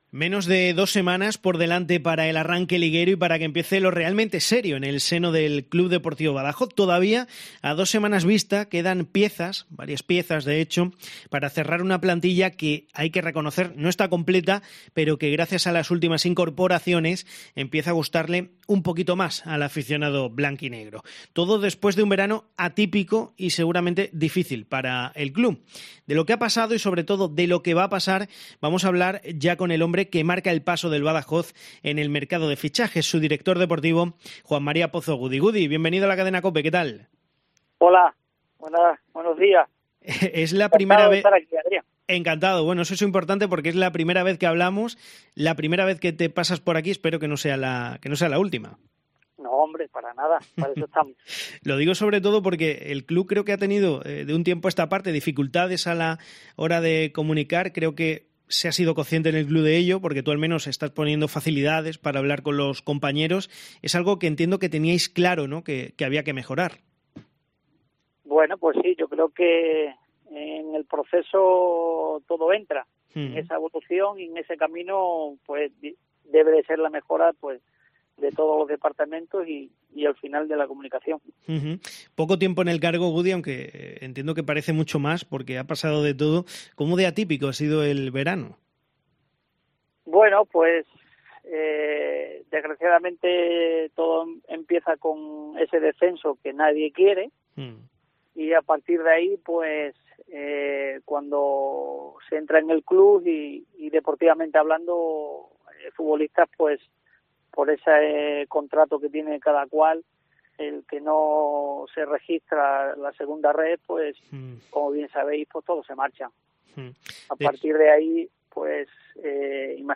Repasamos su entrevista en titulares.